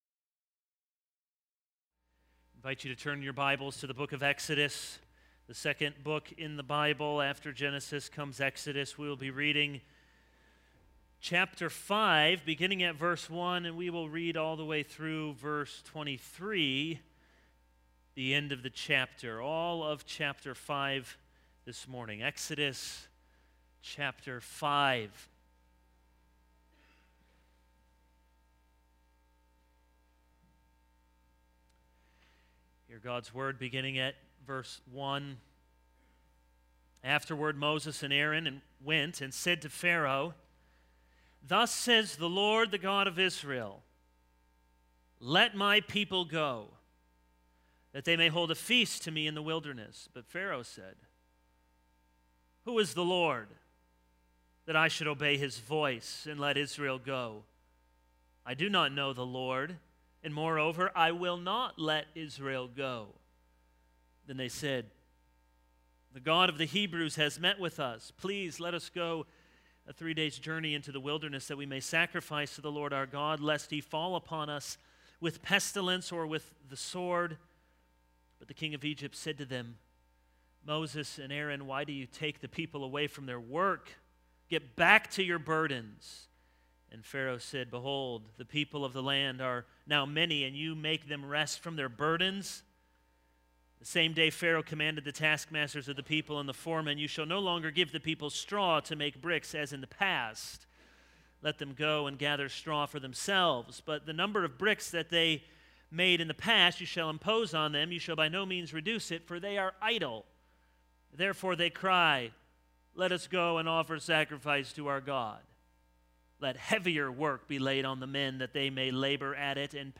This is a sermon on Exodus 5:1-22.